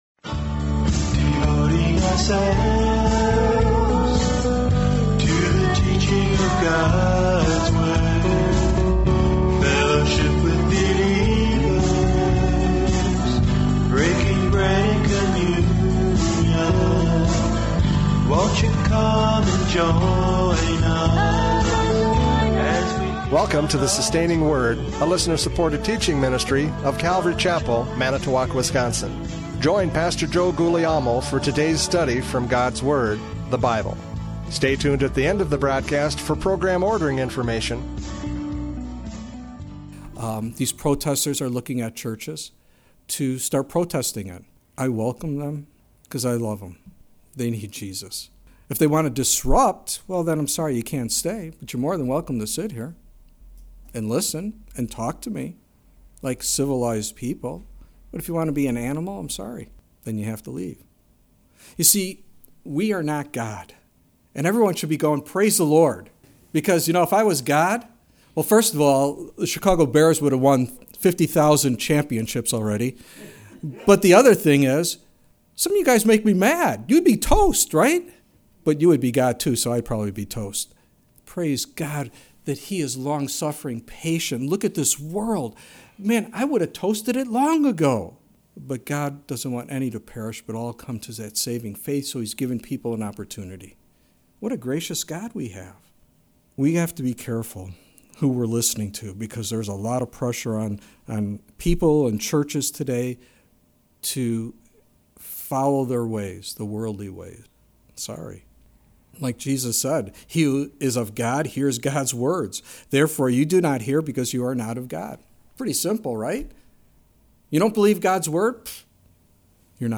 John 8:39-59 Service Type: Radio Programs « John 8:39-59 Physical or Spiritual Birth?